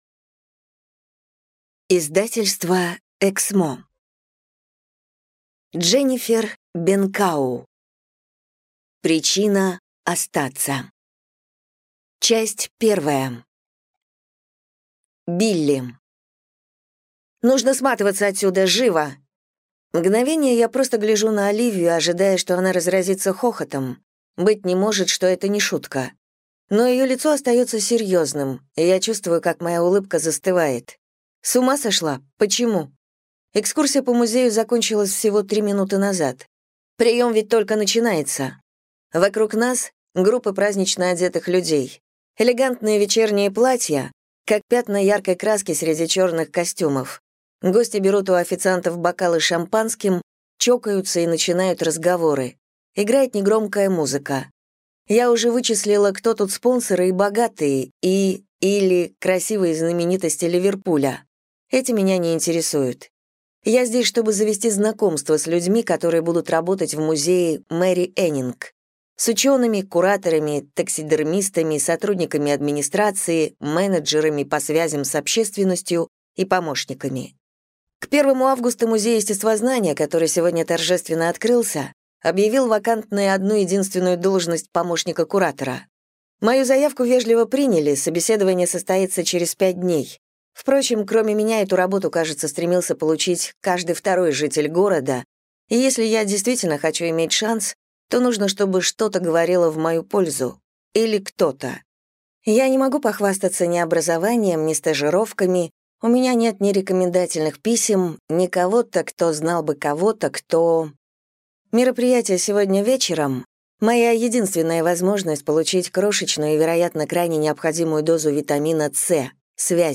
Аудиокнига Причина остаться | Библиотека аудиокниг
Прослушать и бесплатно скачать фрагмент аудиокниги